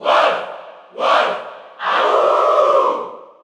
Crowd cheers (SSBU) You cannot overwrite this file.
Wolf_Cheer_Spanish_SSBU.ogg